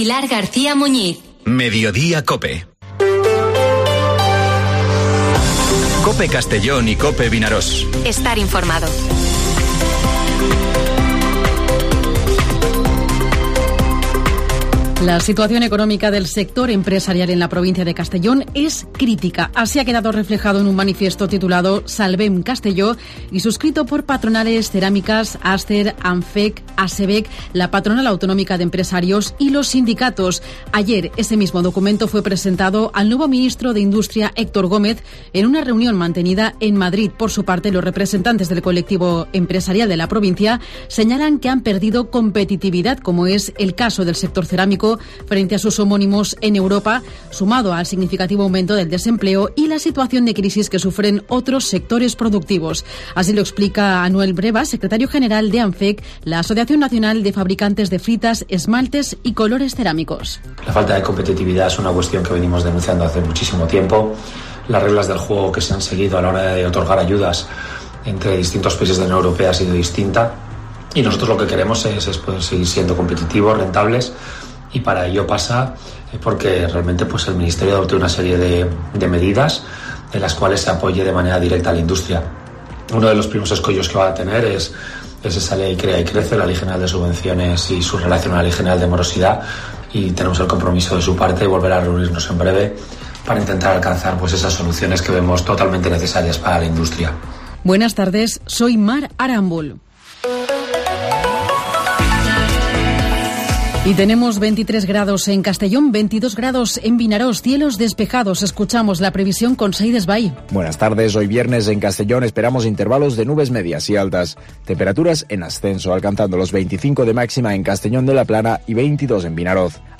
Informativo Mediodía COPE en Castellón (28/04/2023)